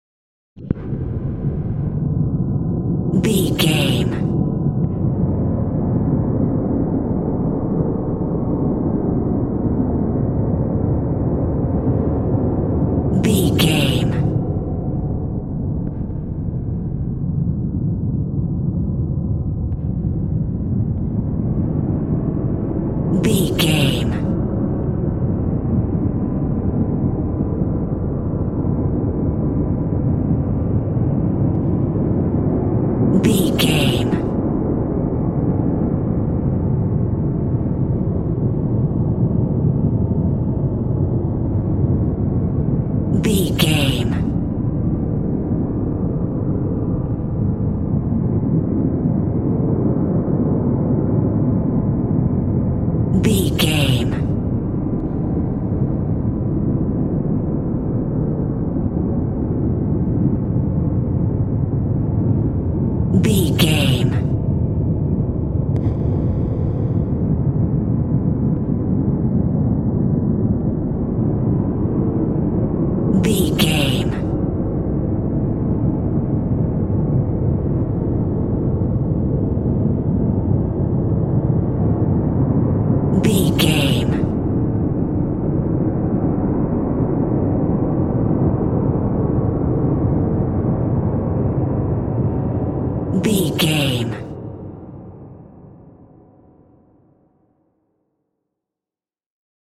Space Sounds.
Atonal
tension
ominous
dark
eerie
Horror synth
Horror Ambience
synthesizer